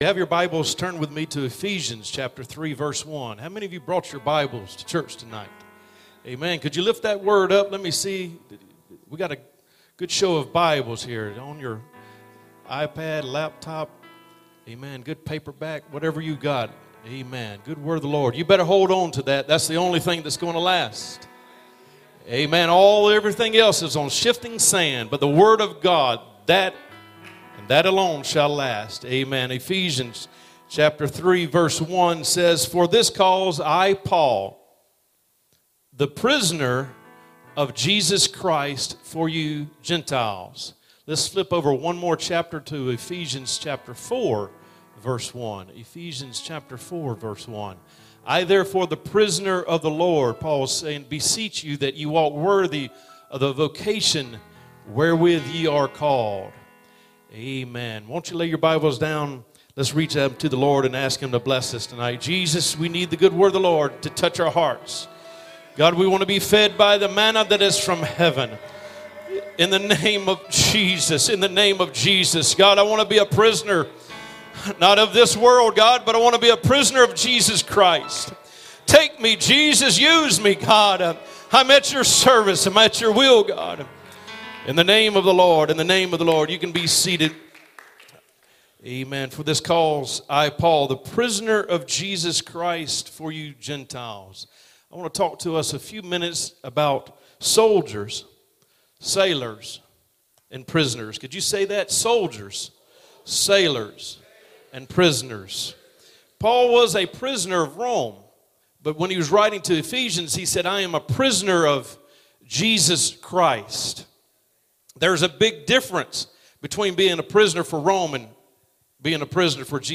First Pentecostal Church Preaching 2021